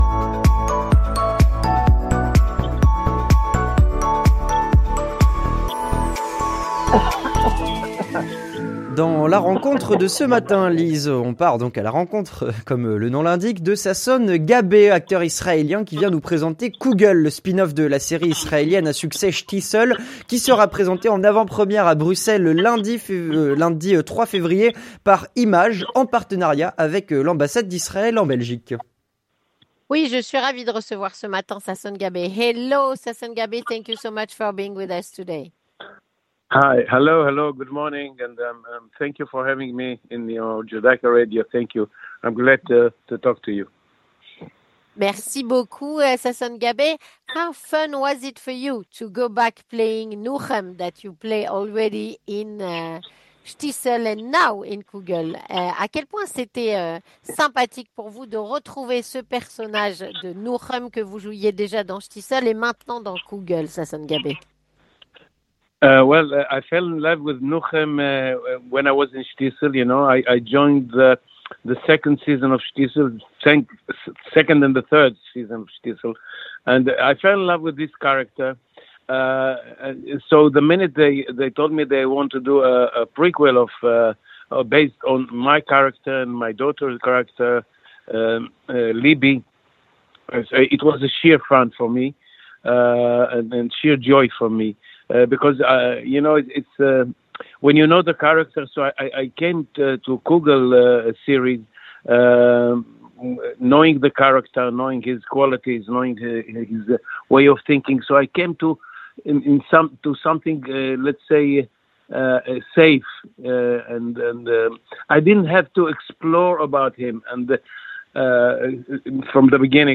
Rencontre avec Sasson Gabai, acteur israélien (31/01/25)